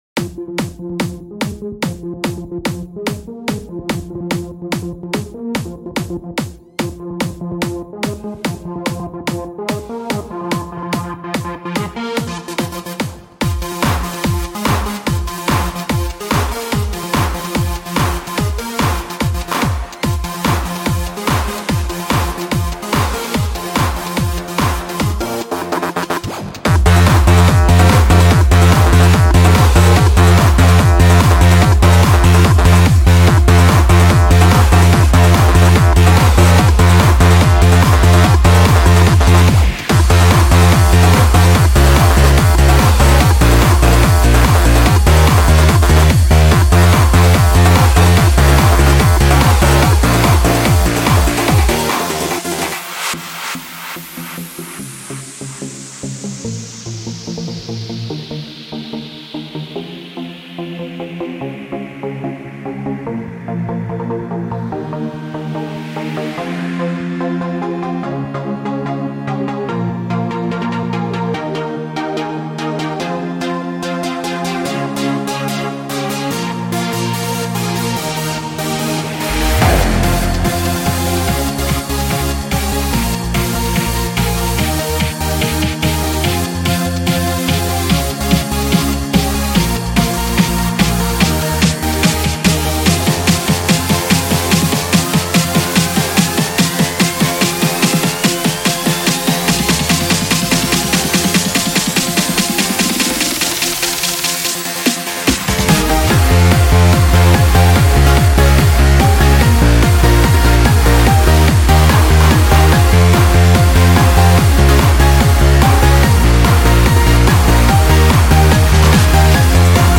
Another HandsUp song.